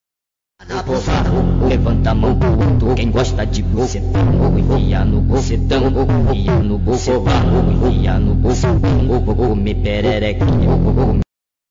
Rigid Fart Sound Button for Meme Soundboard
This trending sound effect is perfect for meme creation, gaming & entertainment.